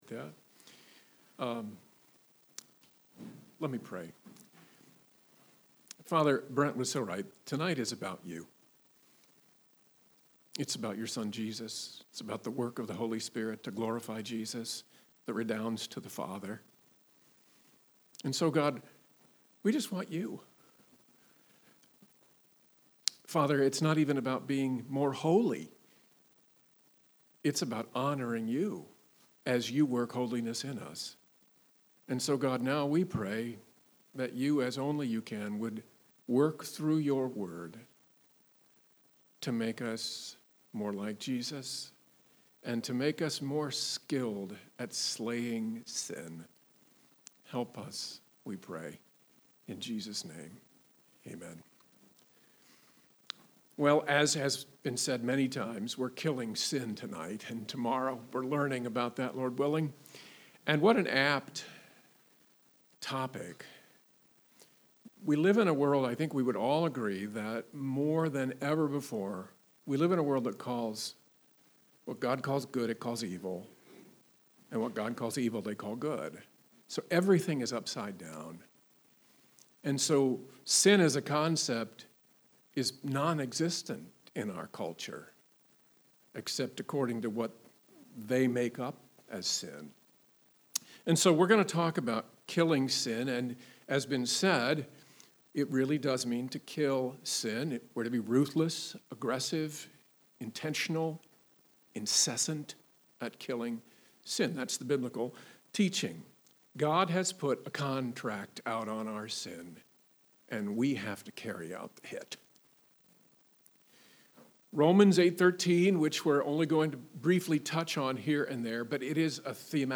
Sermons | The Landing Church
Men's Retreat 2022